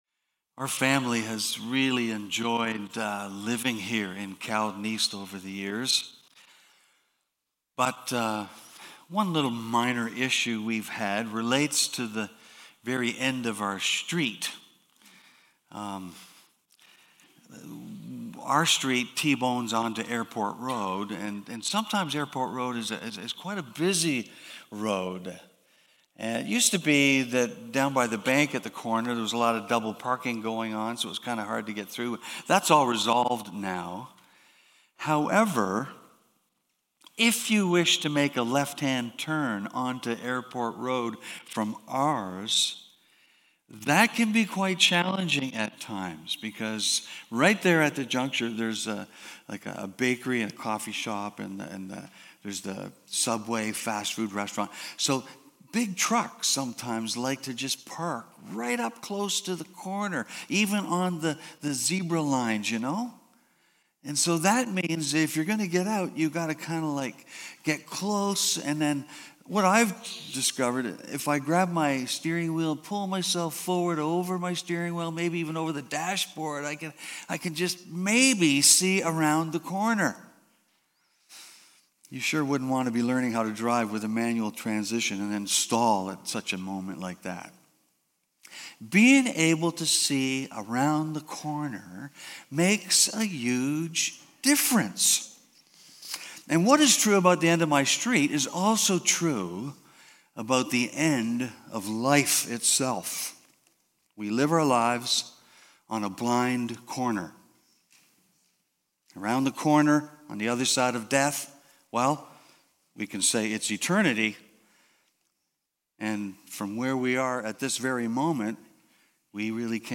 Due to a brief power outage this message was interrupted part way through